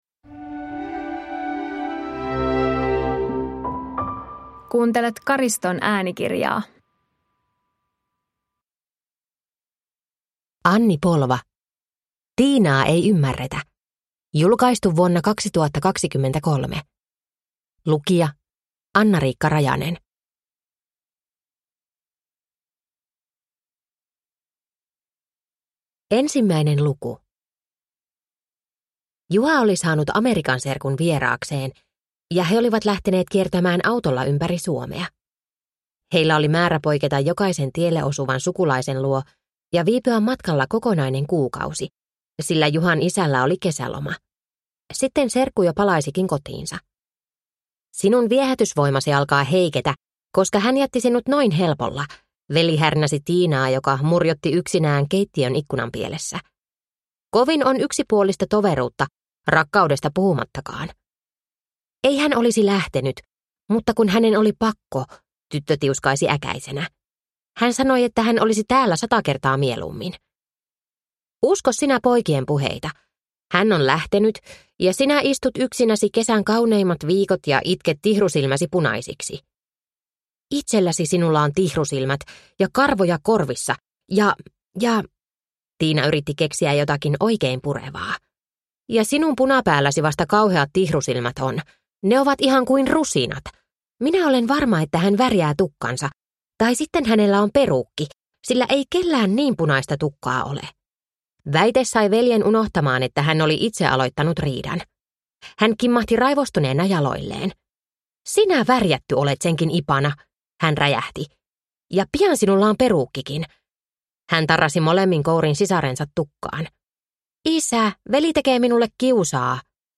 Tiinaa ei ymmärretä (ljudbok) av Anni Polva